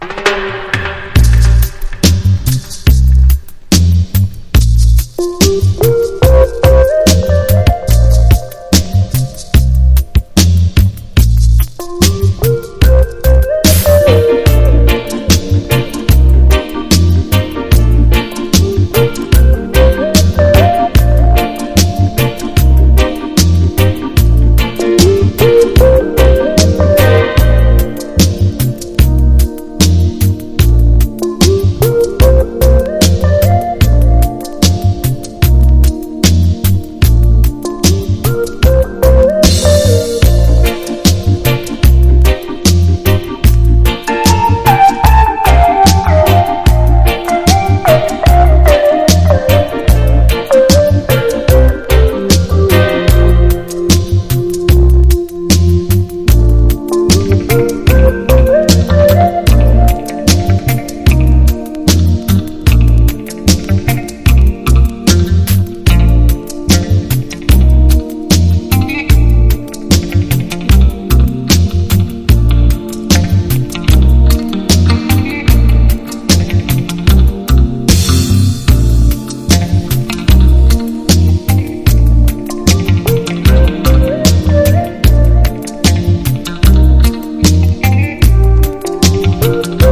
• REGGAE-SKA